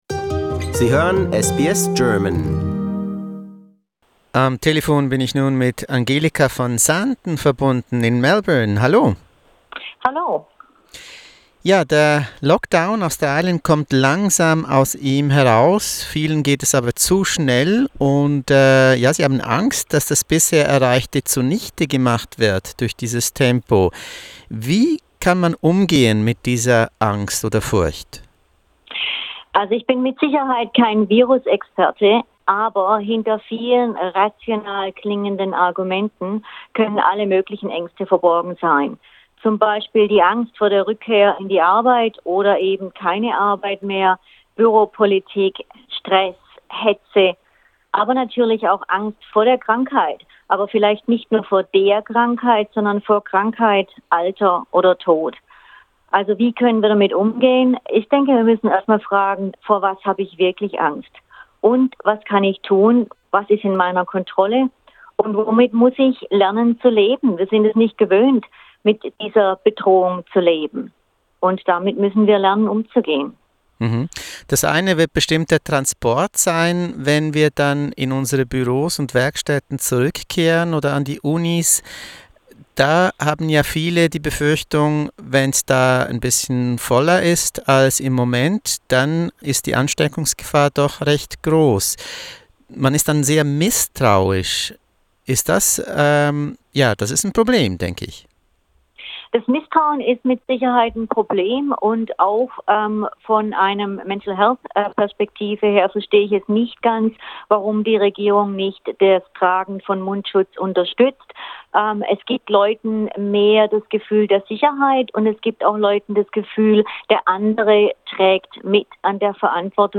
Australien kommt langsam aus dem Lockdown heraus. Was bedeutet das für unsere Psyche? Mehr dazu, in einem Gespräch